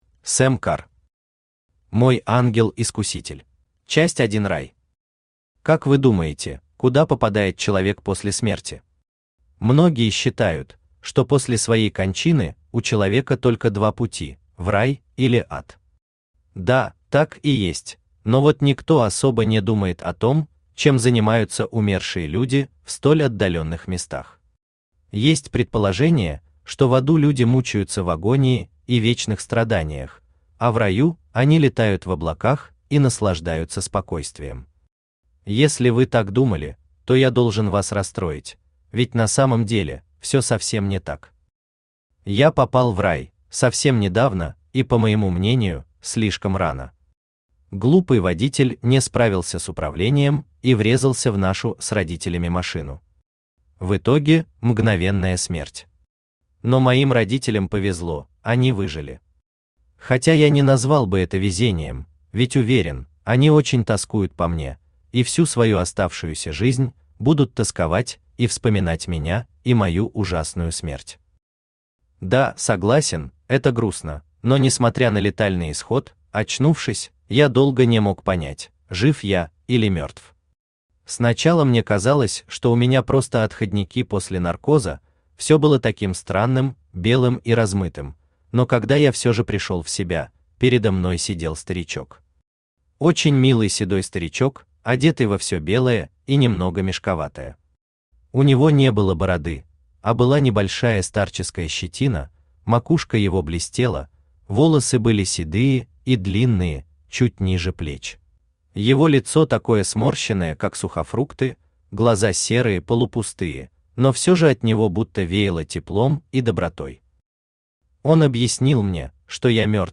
Аудиокнига Мой ангел-искуситель | Библиотека аудиокниг
Aудиокнига Мой ангел-искуситель Автор Сэм Кар Читает аудиокнигу Авточтец ЛитРес.